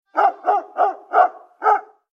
ACTIVITAT 8. QUIN ANIMAL FA AQUEST SOROLL?
gos.mp3